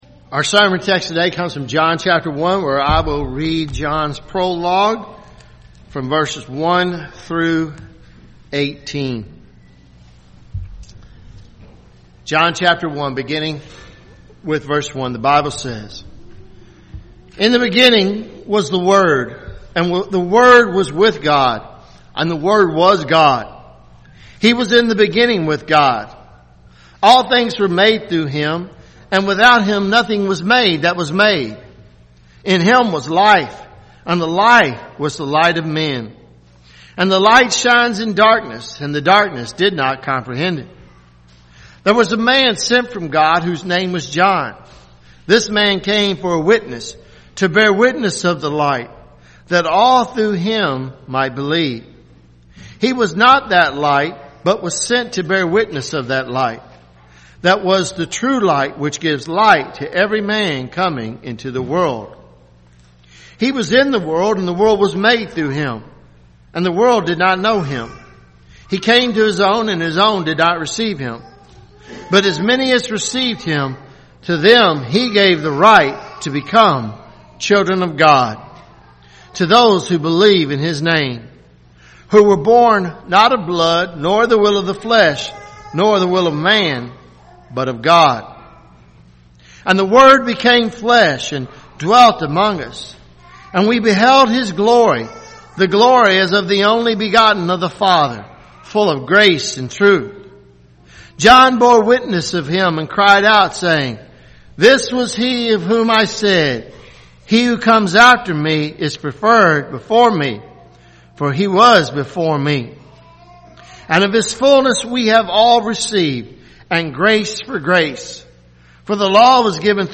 Sermons Dec 23 2018 “The Word Made Flesh